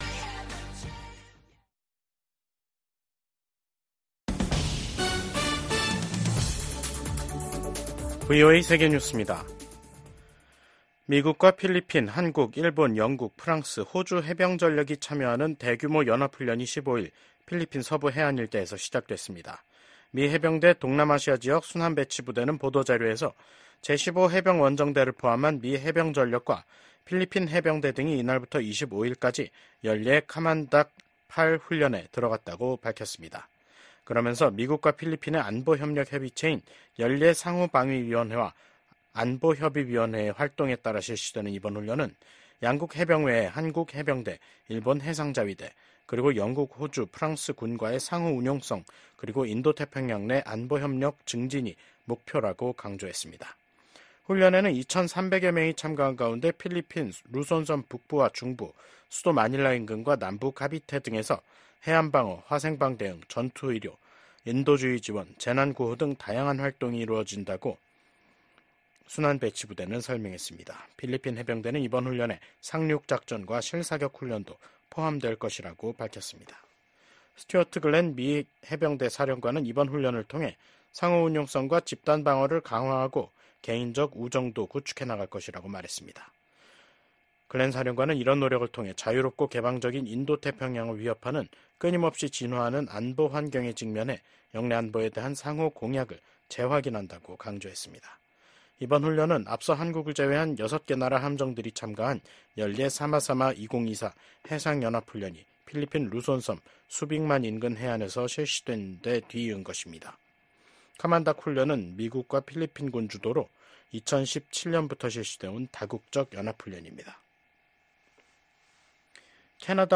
VOA 한국어 간판 뉴스 프로그램 '뉴스 투데이', 2024년 10월 15일 3부 방송입니다. 북한의 ‘한국 무인기 평양 침투’ 주장으로 한반도 긴장이 고조되고 있는 가운데 북한군이 오늘(15일) 남북을 잇는 도로들을 폭파했습니다. 16일 서울에서 미한일 외교차관협의회가 열립니다.